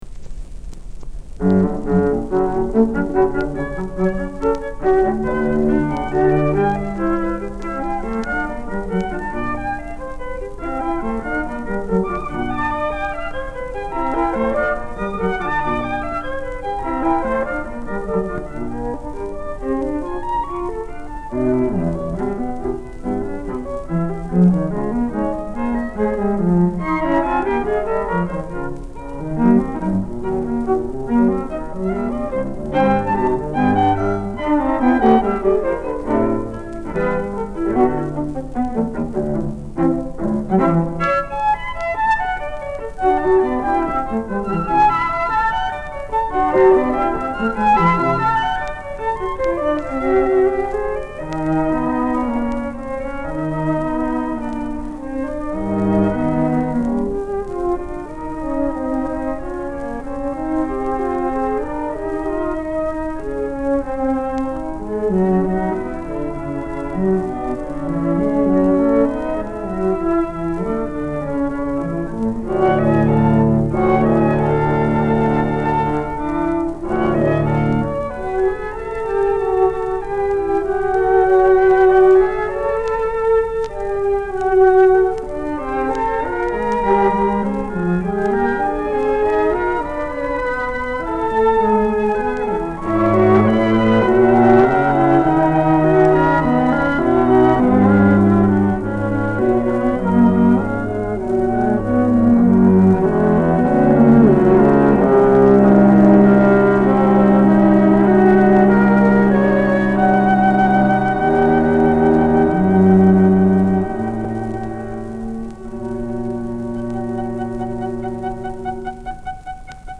Allegro
shellac 12"